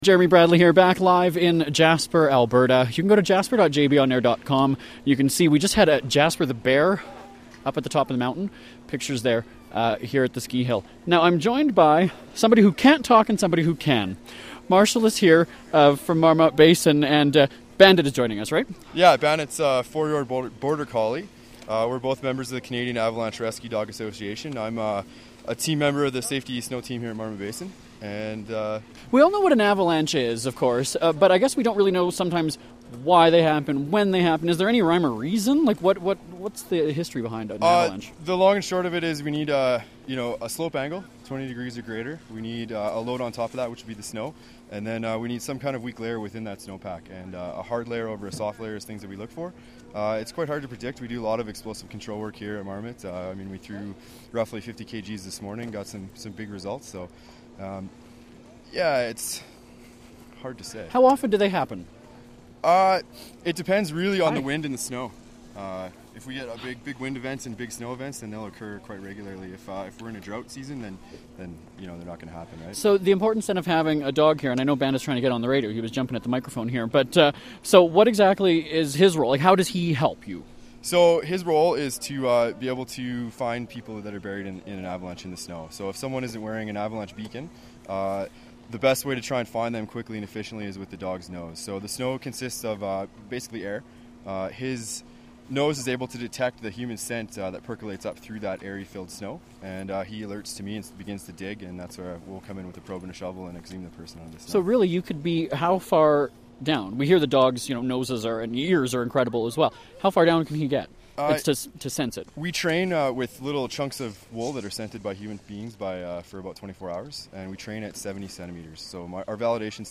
Live Event
An interesting moment just as we were wrapping up the segment: a young boy came flying towards us and crashed into the orange netting. Listen for that interesting live radio moment.